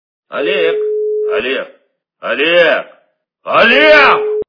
» Звуки » Именные звонки » Именной звонок для Олега - Олег, Олег, Олег, Олег
При прослушивании Именной звонок для Олега - Олег, Олег, Олег, Олег качество понижено и присутствуют гудки.